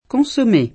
consommé [fr.
k6Som%] s. m. (gastron.); pl. consommés [id.] — francesismo per «brodo ristretto» — adatt. talvolta in it. come consommè [